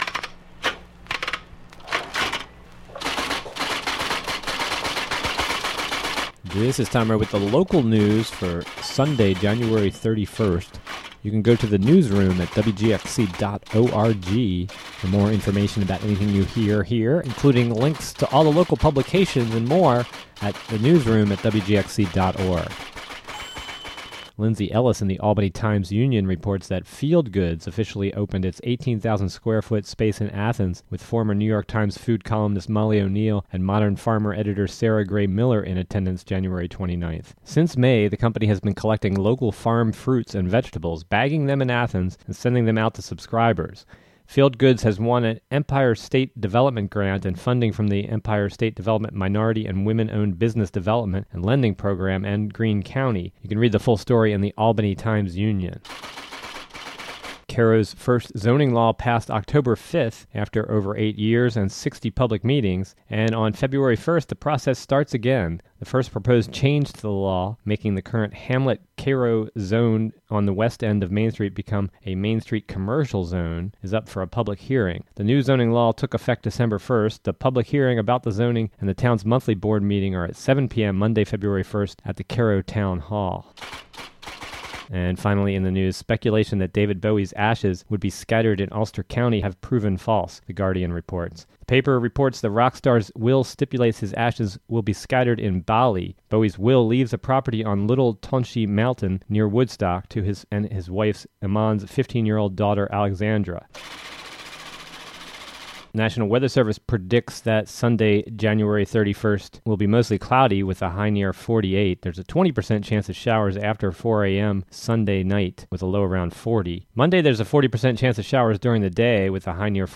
WGXC local news audio link Jan 31, 2016 12:05 am DOWNLOAD or play the audio version of the local headlines and weather for Sun., Jan. 31 (2:43).